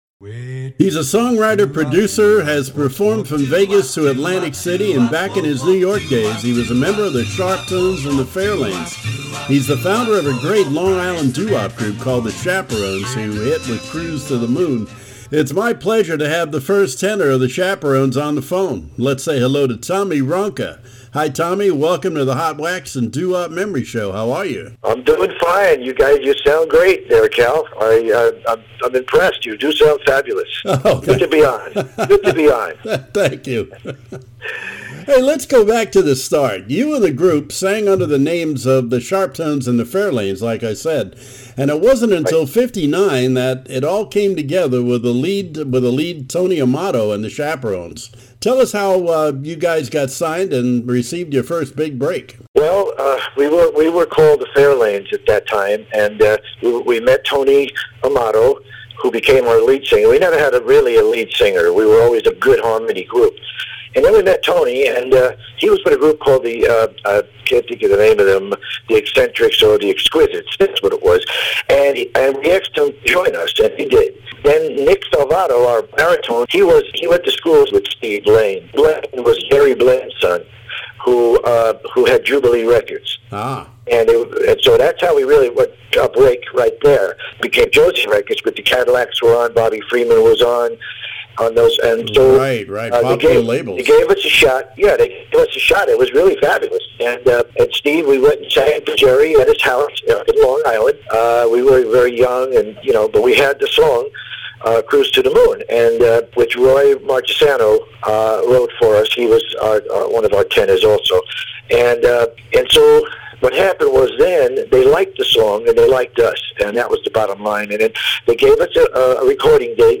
Hot Wax California Interview